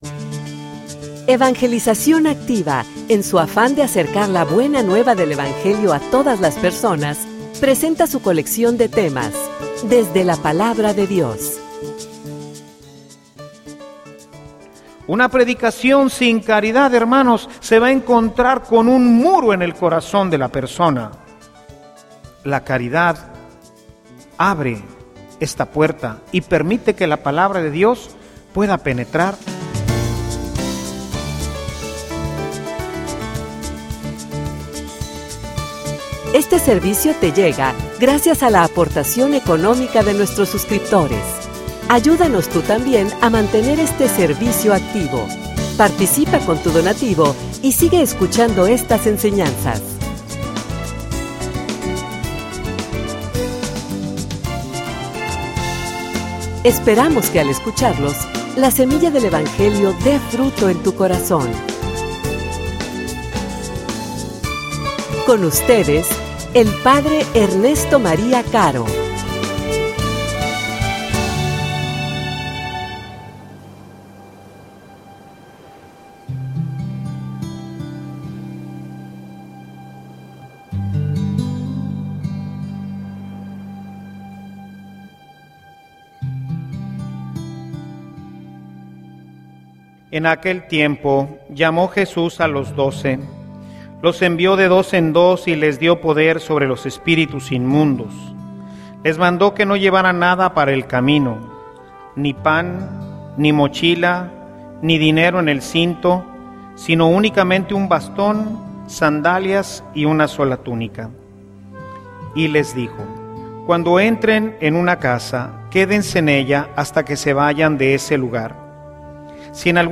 homilia_Sin_caridad_no_hay_Evangelizacion.mp3